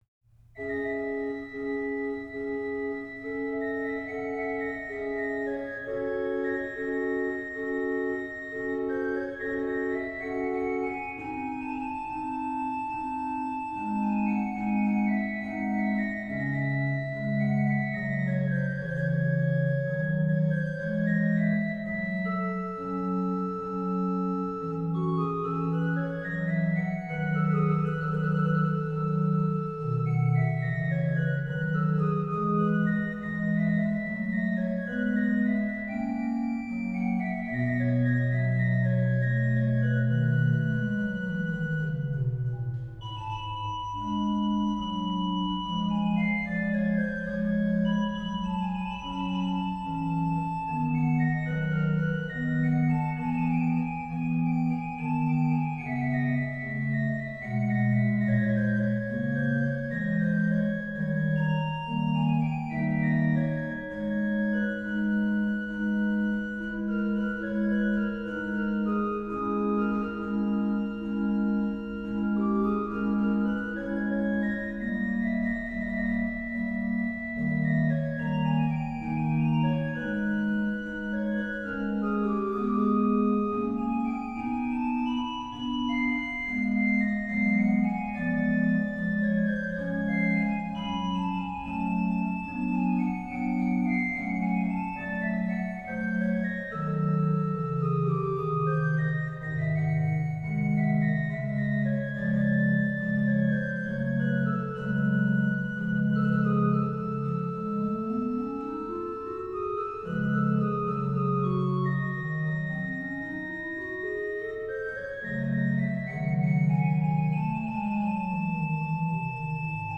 rh: OW: Ged8, Nas3
lh: HW: Rfl8